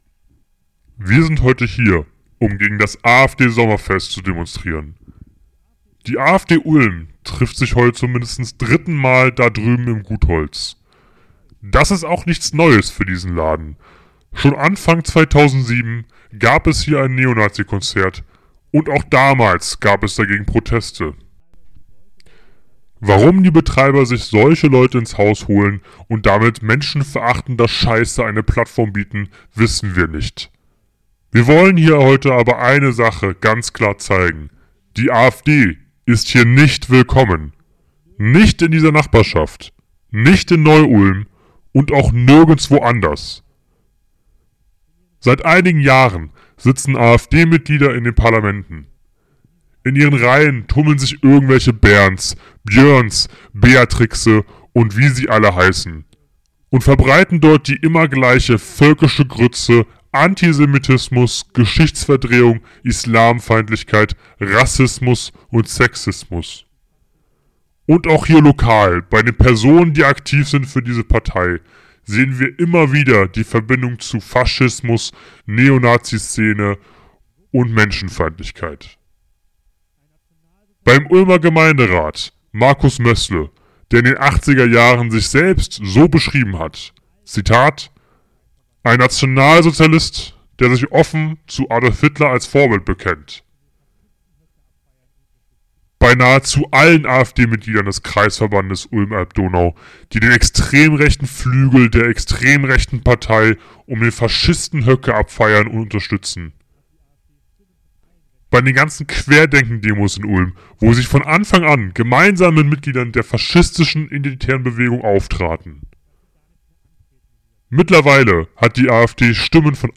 Heute haben um die 50 Leute gegen das AfD Sommerfest im Gut Holz demonstriert. Hier zum nachhören ein Redebeitrag: